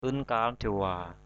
/ɯn-ka:r de̞-wa/ (d.) tên bạn thân của hiệp sĩ Déwa Mano trong văn chương Chàm.